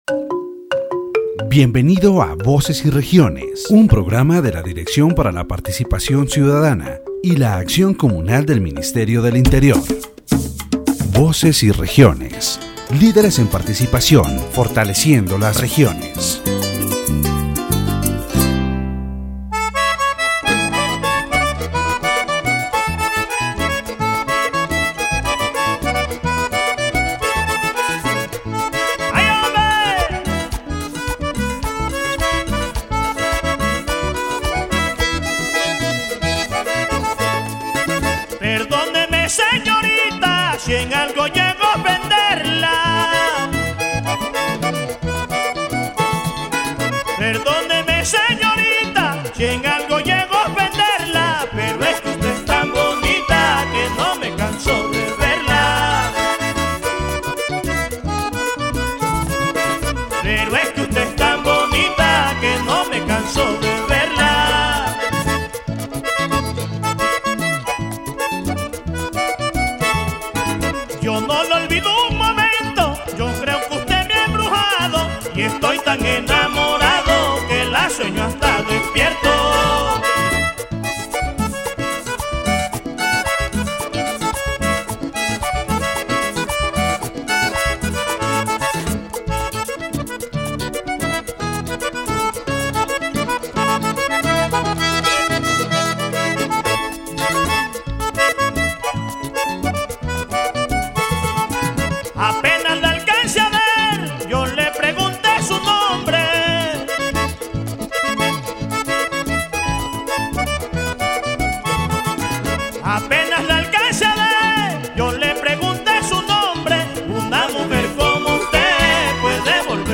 The program discusses the challenges and opportunities for young people in the region, emphasizing youth participation in public policy and community projects. Topics include access to higher education, social inclusion, and government and private sector programs. The interview also highlights the cultural diversity of La Guajira and strategies to address issues like drug use and teenage pregnancy.